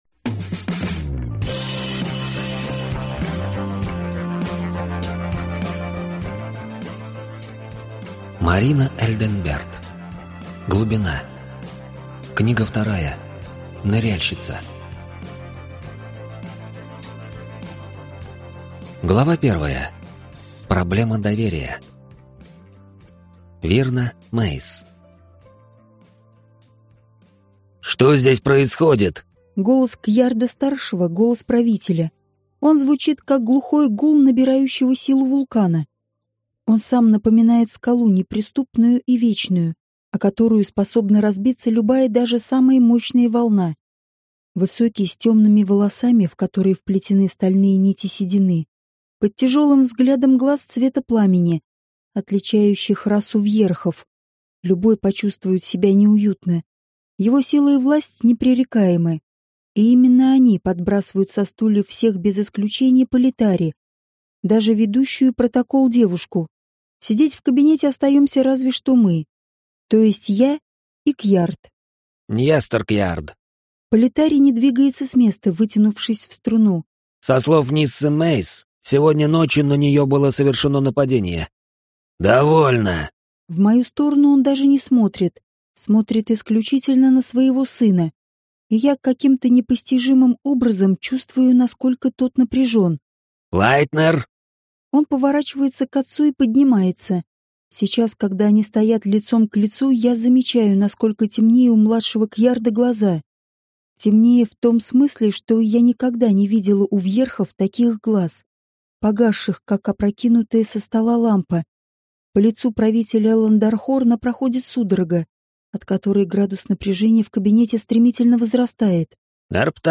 Аудиокнига Ныряльщица | Библиотека аудиокниг
Прослушать и бесплатно скачать фрагмент аудиокниги